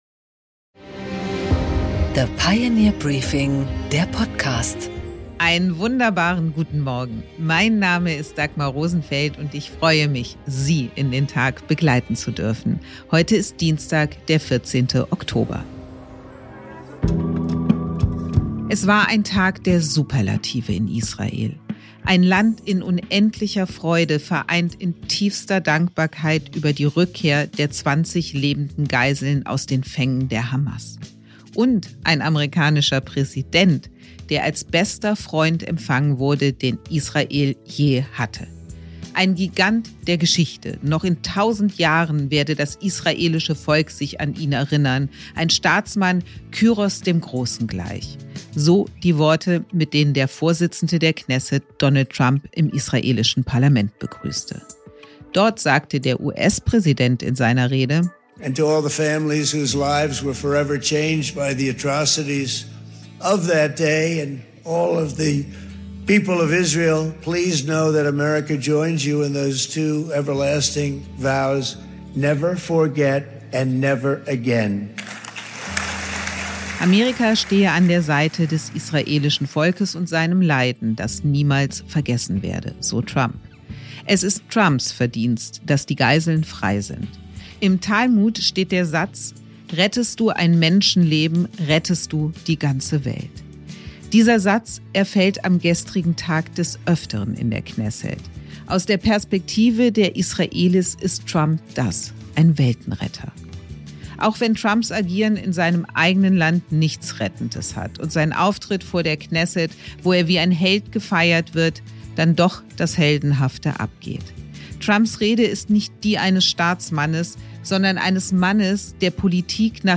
Dagmar Rosenfeld präsentiert das Pioneer Briefing
Ein Kommentar von Dagmar Rosenfeld.
Im Gespräch: Prof. Peter Neumann, Extremismus-Forscher vom King’s College London, analysiert im Gespräch mit Dagmar Rosenfeld, warum viele Hamas-Kämpfer Trumps Friedensplan nicht folgen wollen und welche Herausforderungen auf dem Weg zu einem dauerhaften Frieden bleiben.